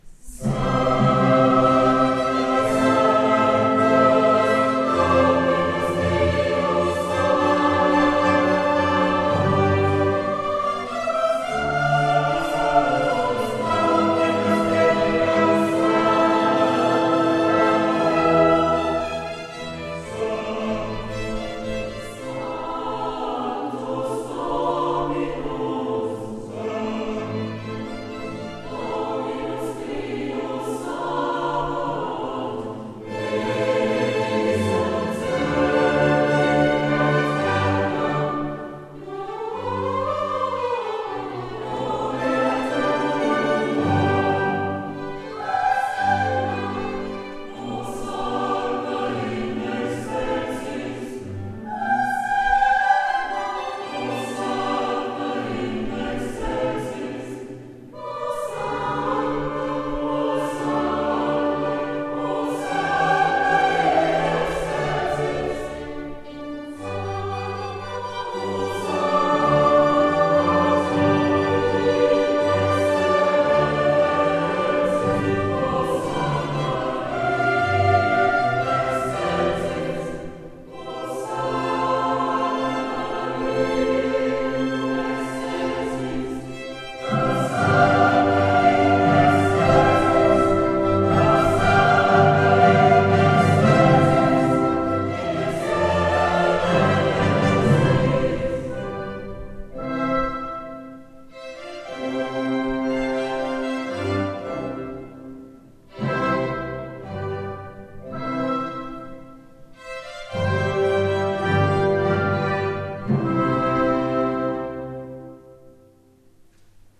Am Allerheiligentag 2021
der Kirchenchor samt Orchester
Nach über 200 Jahren wurde so ein altes Musikstück eines aus Vöcklamarkt stammenden Komponisten wieder zum Erklingen gebracht und begeisterte die Feiergemeinde beim Festgottesdienst.